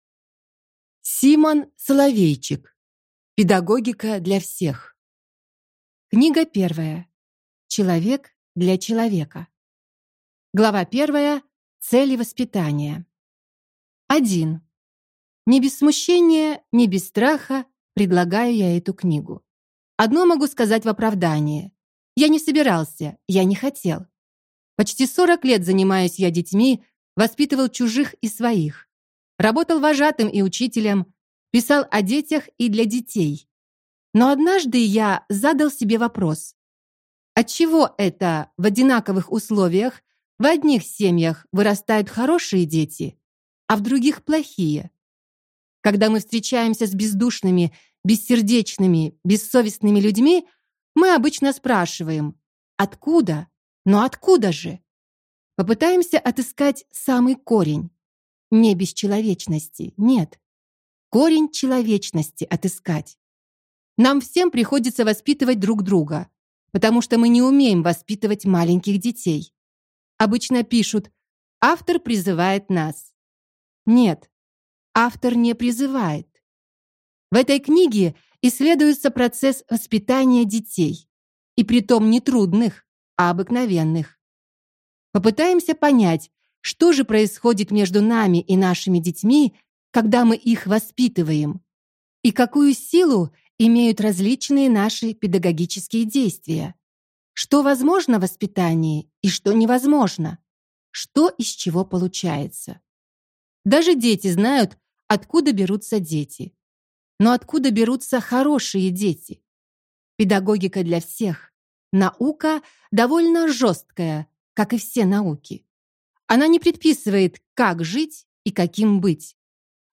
Аудиокнига Педагогика для всех | Библиотека аудиокниг
Прослушать и бесплатно скачать фрагмент аудиокниги